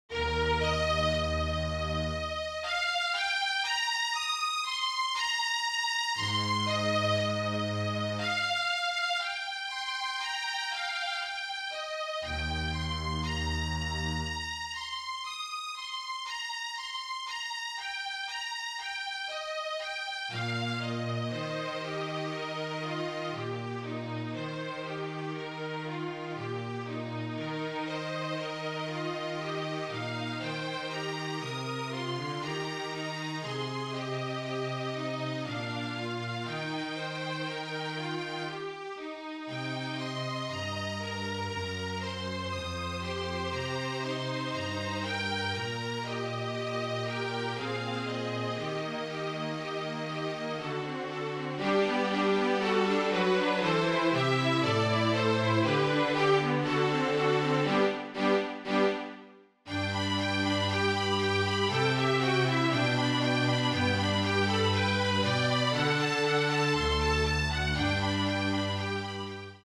Flute, Violin and Cello (or Two Violins and Cello)
MIDI